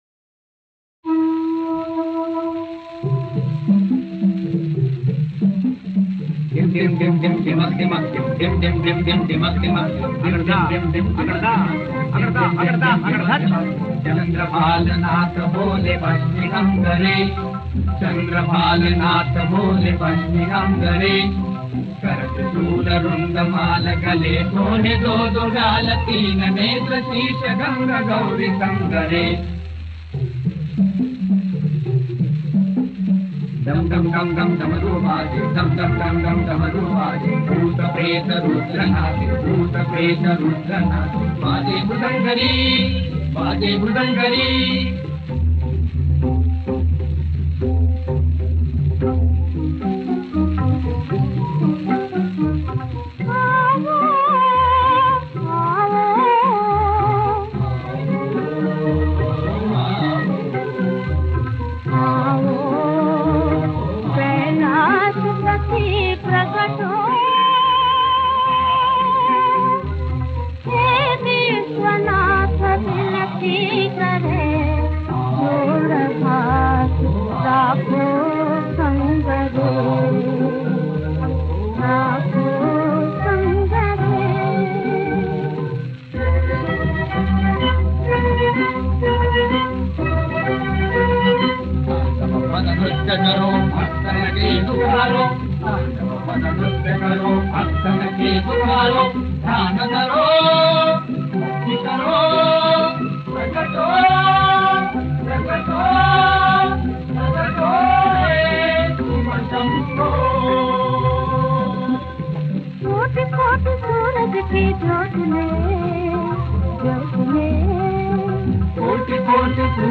Chorus